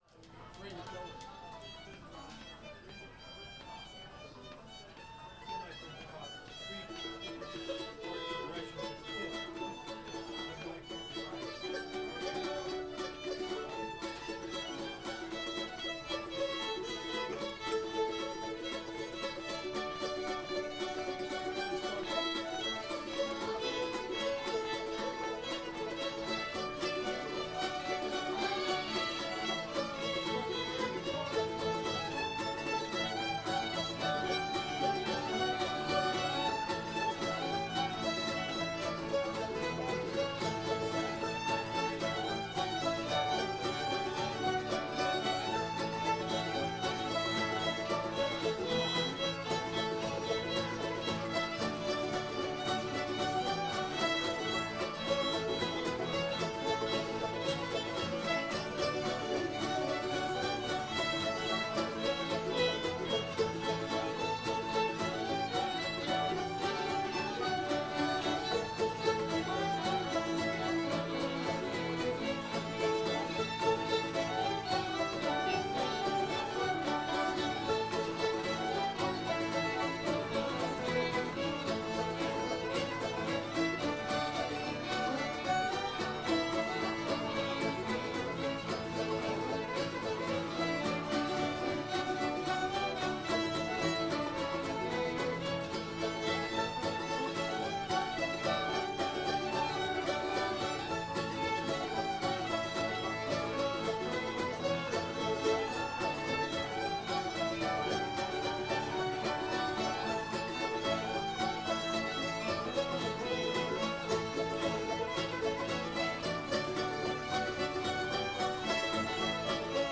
texas [A]